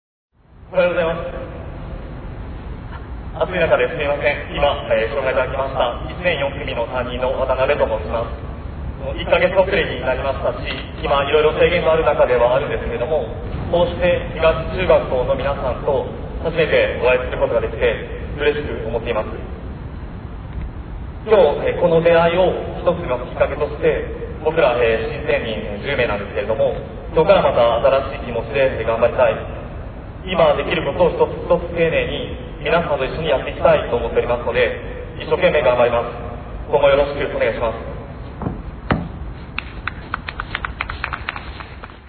先週の登校日の際に、４月に着任された教職員の紹介があり、３人の先生がそれぞれのグループでスピーチしてくれました。